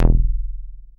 DOWN BASS E2.wav